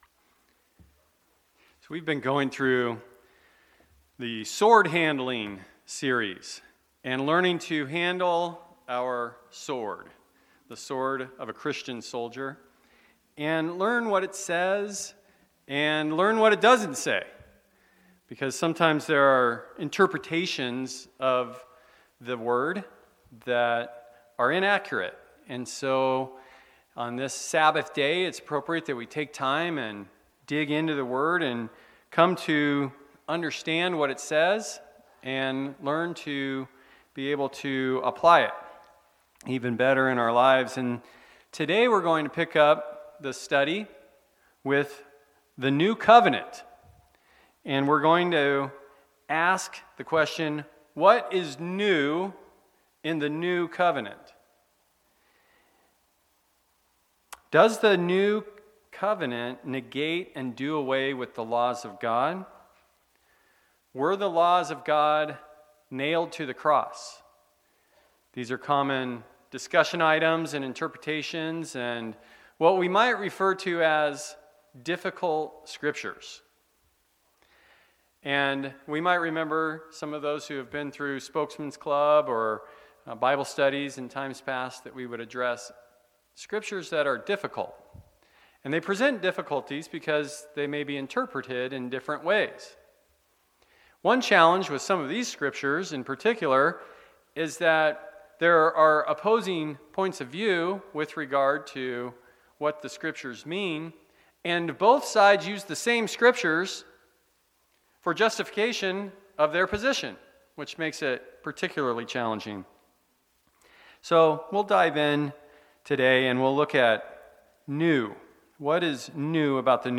Sermons
Given in Phoenix Northwest, AZ